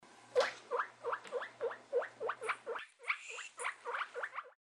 Звуки морских свинок
Голос морской свинки